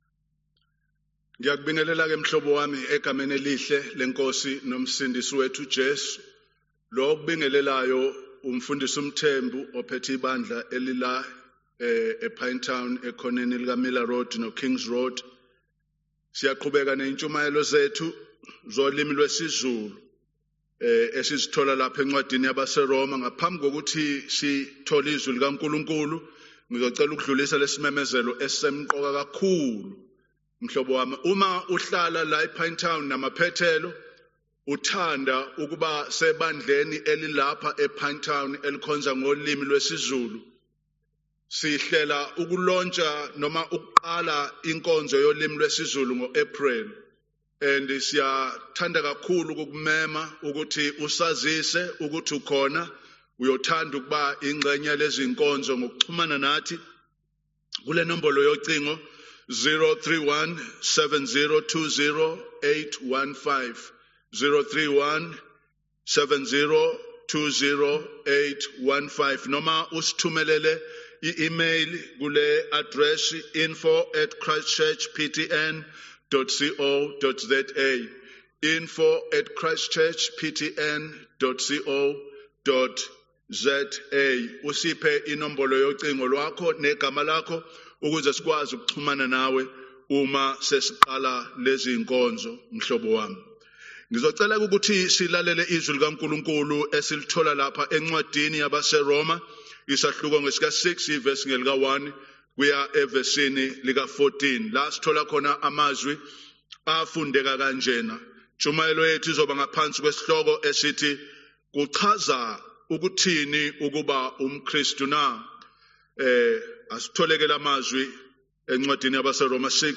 Zulu Sermon